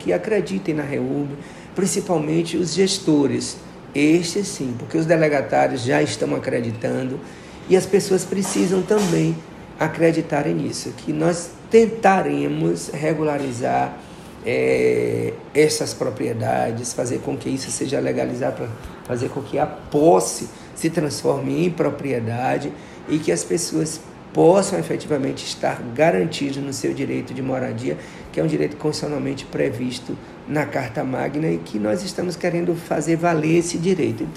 O Presidente do Fórum, Desembargador Rotondano, faz questão de deixar um recado para a população sobre a necessidade de acreditarem na Reurb.